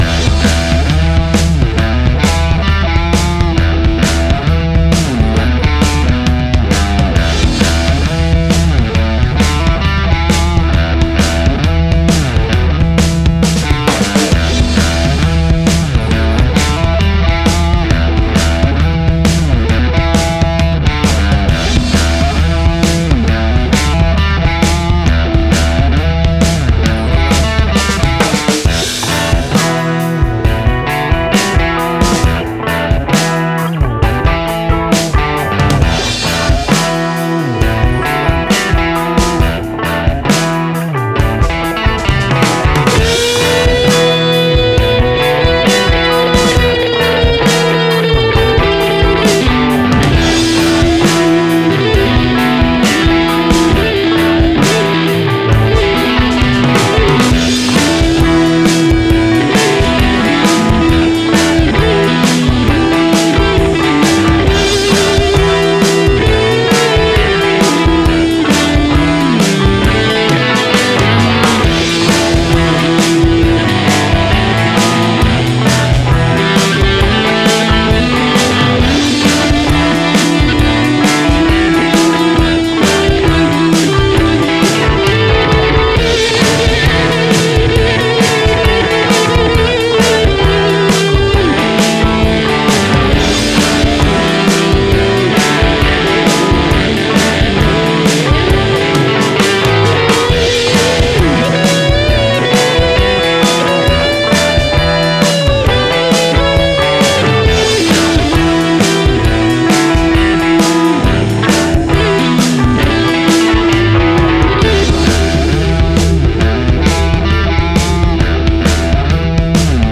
WAV Sample Rate: 24-Bit stereo, 44.1 kHz
Tempo (BPM): 134